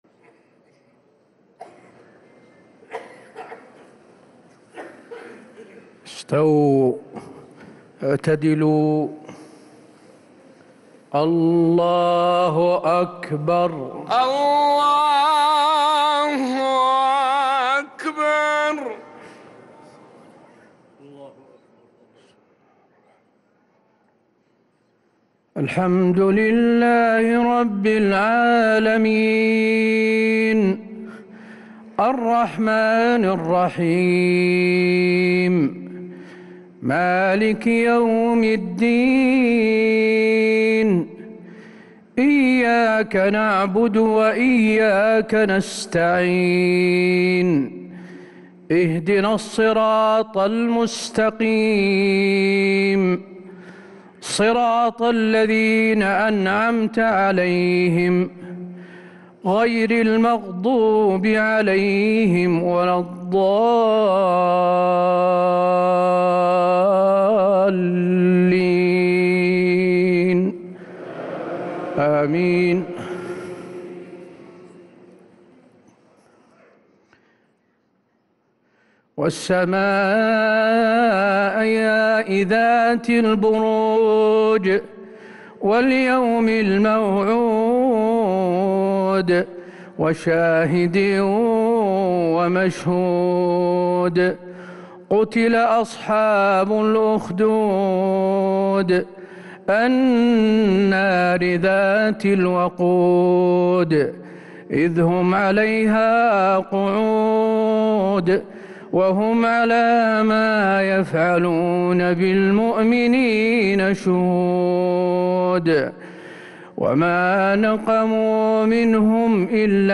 صلاة العشاء للقارئ حسين آل الشيخ 30 شعبان 1444 هـ
تِلَاوَات الْحَرَمَيْن .